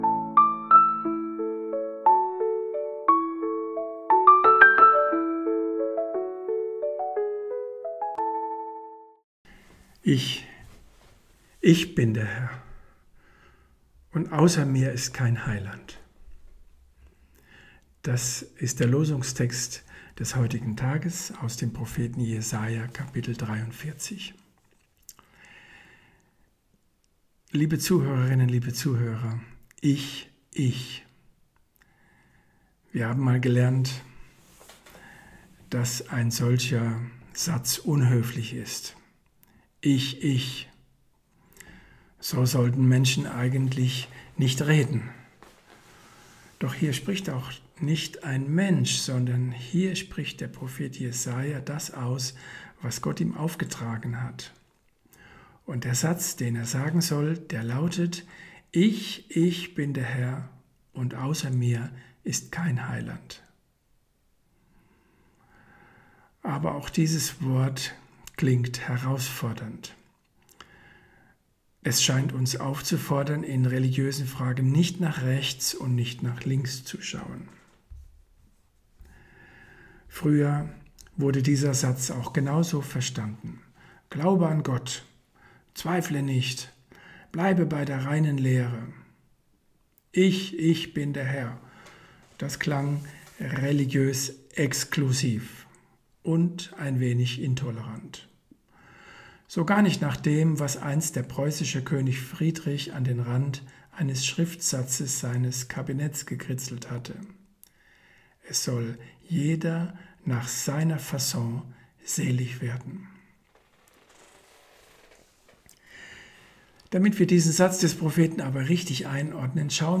Losungsandacht für Montag, 23.03.2026 – Prot.